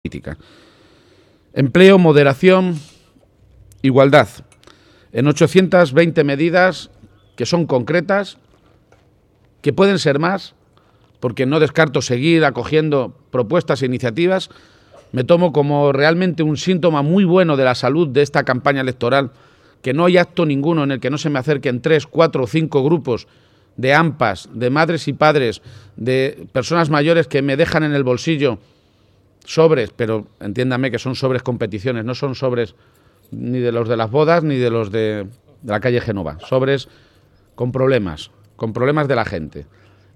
Audio Page-presentación programa electoral 1